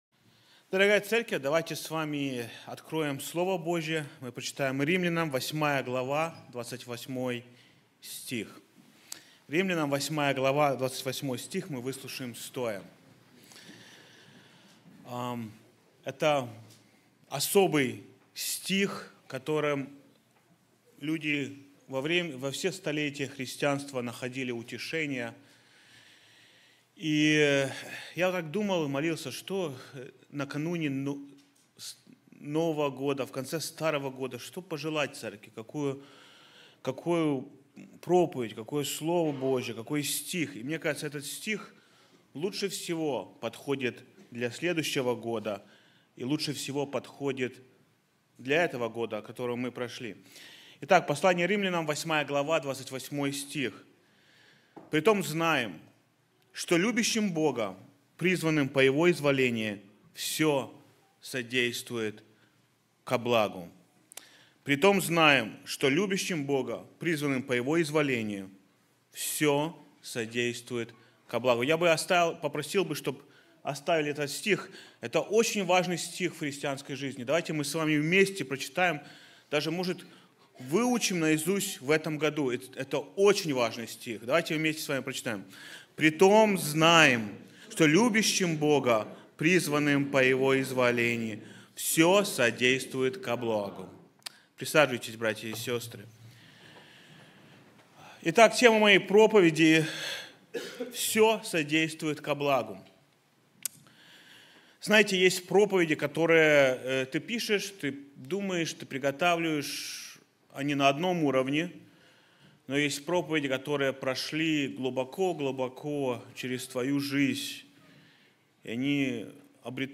sermon-all-thinks-work-for-good.mp3